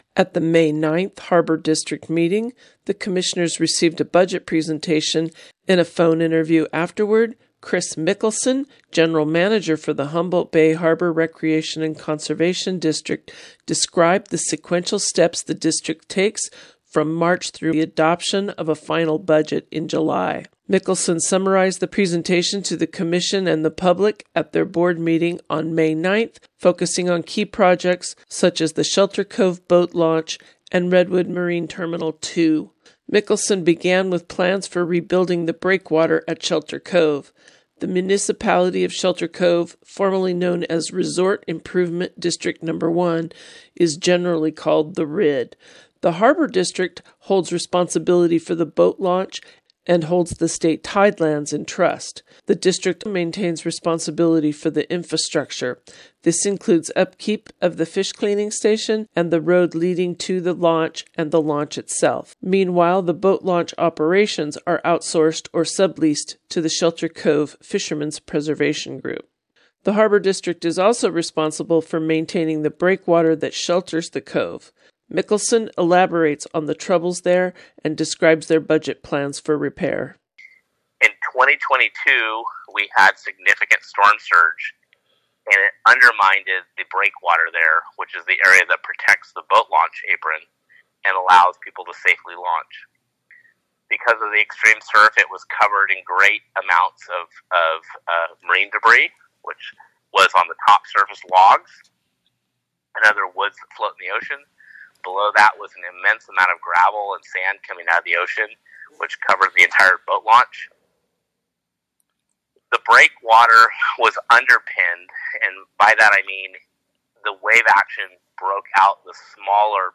KMUD News brings you this report on the Harbor District's progress toward funding repairs to Shelter Cove's breakwater....